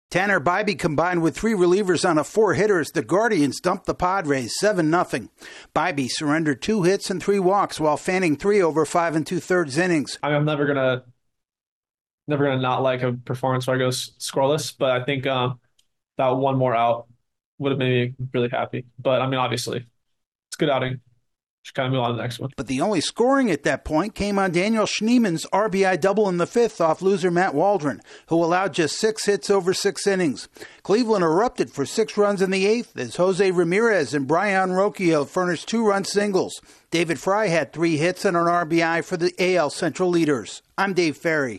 The Guardians ride strong pitching and a late explosion to beat the Padres. AP correspondent